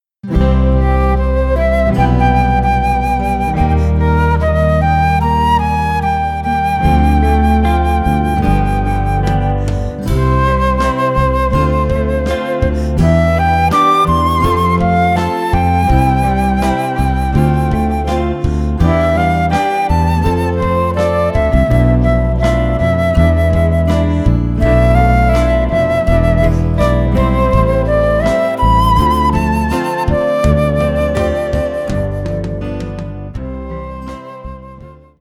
Gattung: Flöte mit Online-Audio
Besetzung: Instrumentalnoten für Flöte